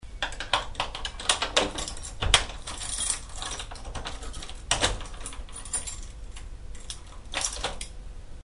Bruits de clés dans une serrure